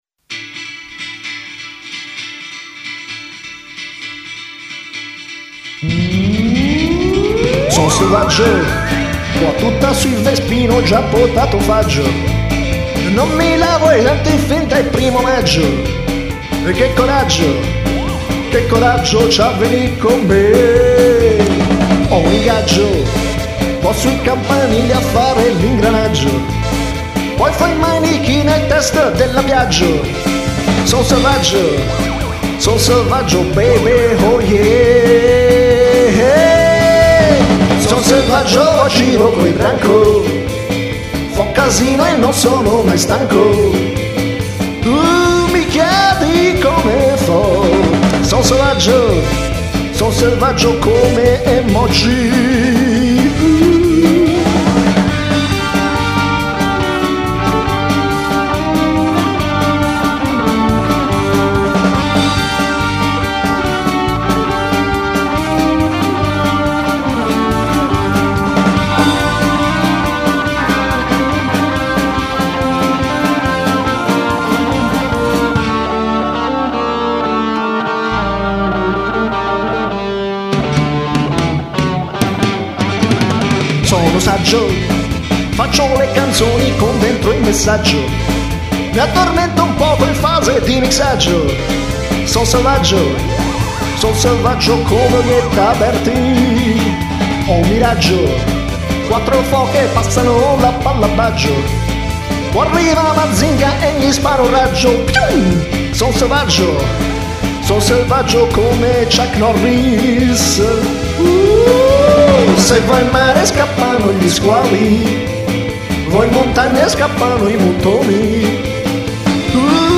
Una canzone arrabbiatissima sul mio lato oscuro.
cantato con passione